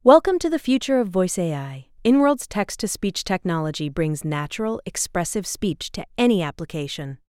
multilingual text-to-speech voice-cloning
Ultra-fast, cost-efficient text-to-speech with ~120ms latency and 15-language support